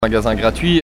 prononciation
magasin_gratuit_prononciation.mp3